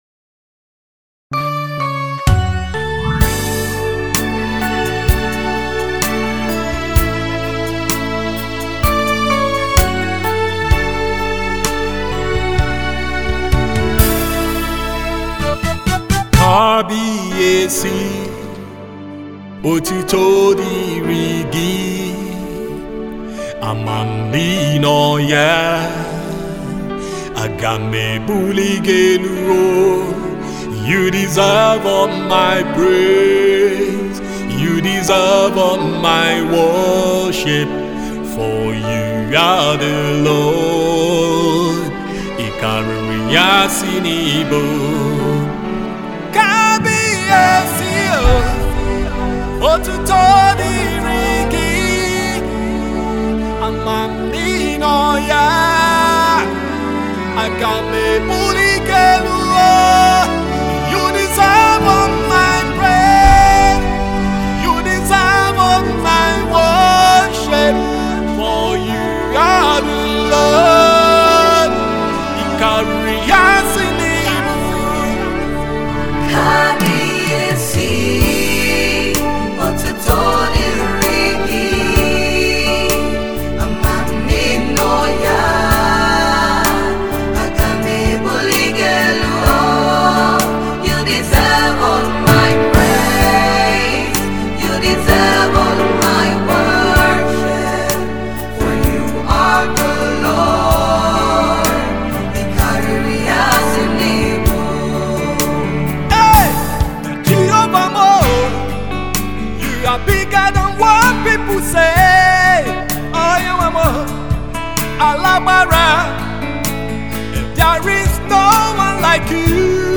a very strong and powerful worship song
gospel songs